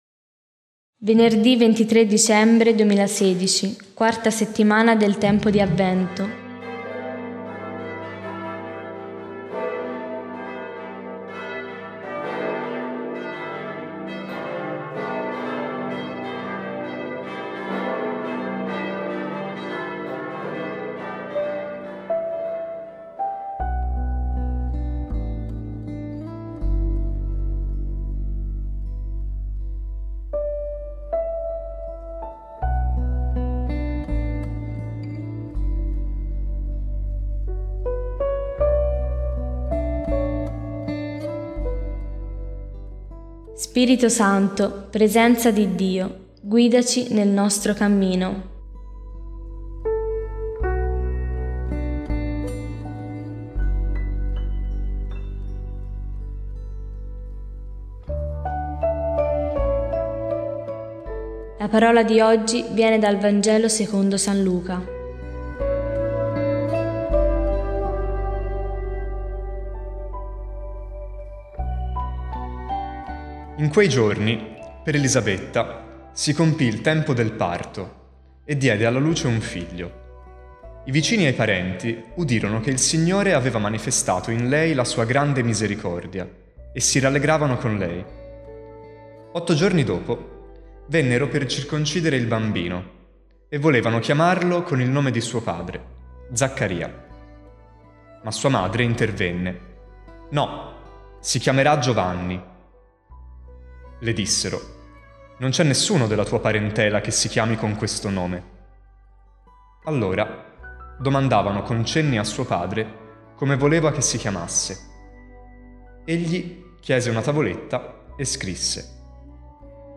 Musica: Conversation with Father – Zbigniew Preisner